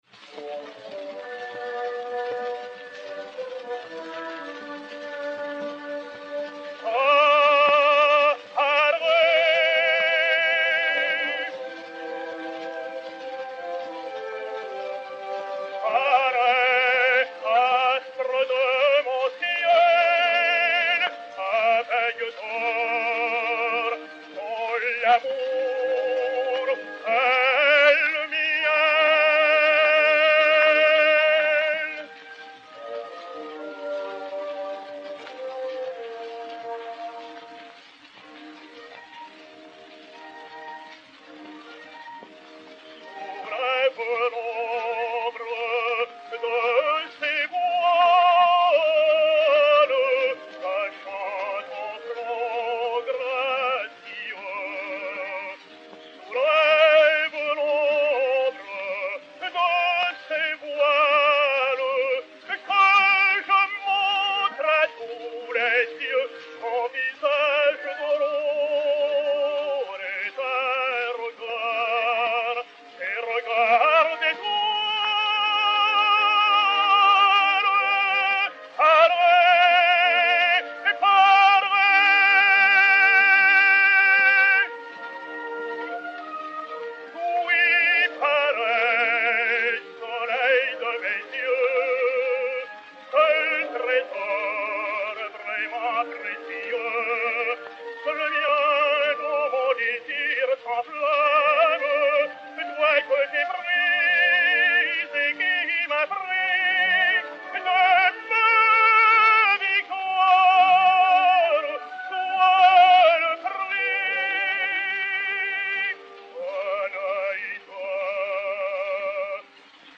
Agustarello Affre (Zarâstra) et Orchestre